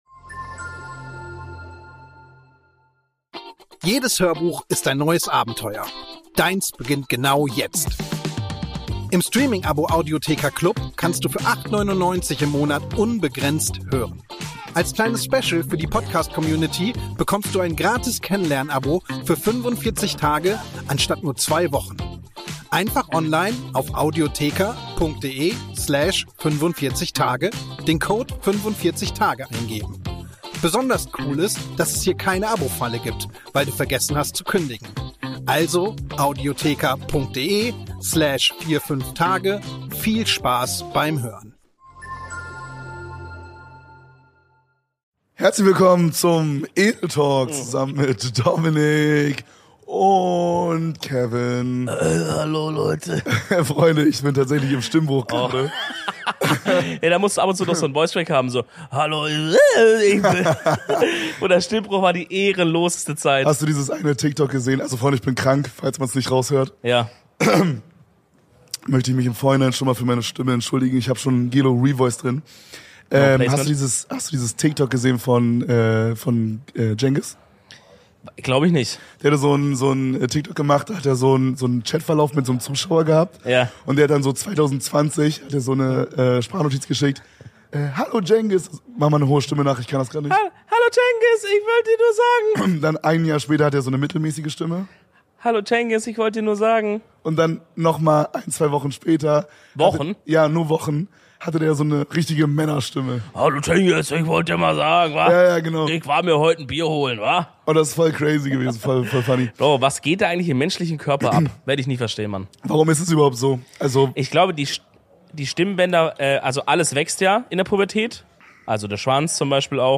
Heute befinden sich die Jungs im XPerion und nehmen